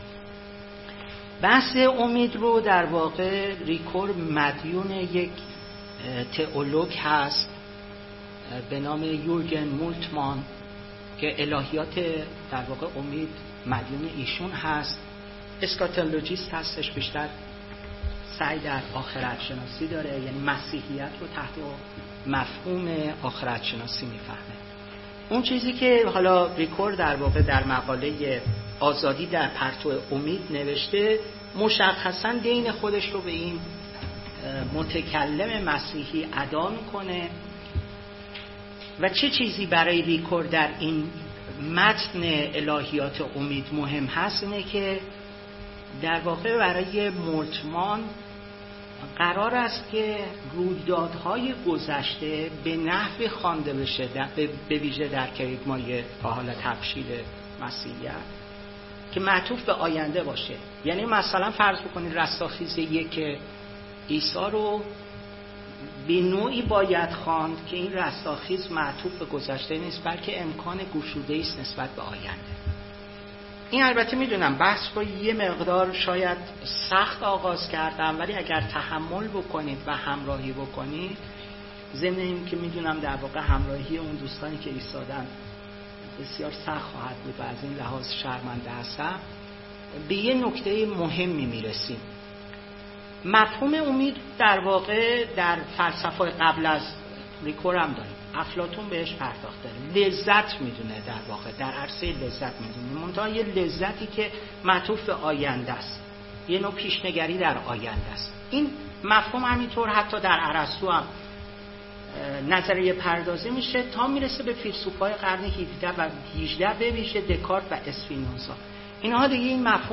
سخنرانی
در جلسه رونمایی و معرفی کتاب زمان و حکایت پل ریکور